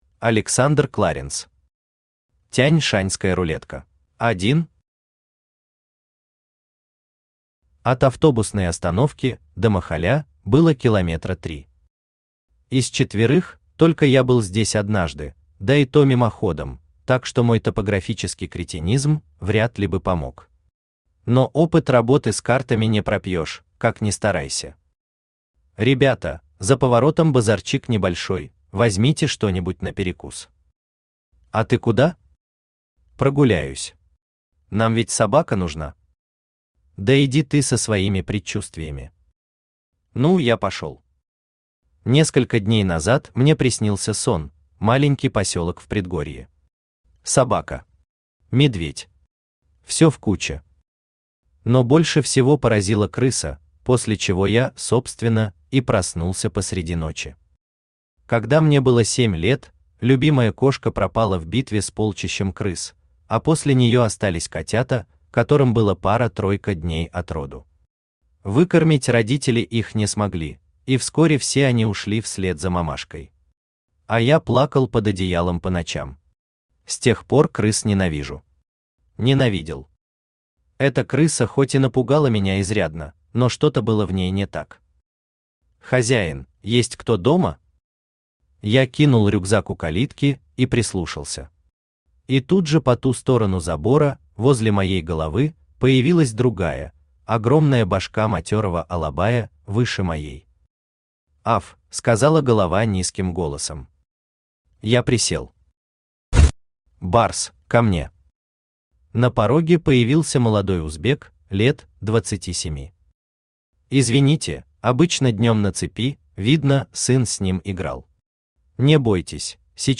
Aудиокнига Тянь-Шаньская рулетка Автор Александр Кларенс Читает аудиокнигу Авточтец ЛитРес.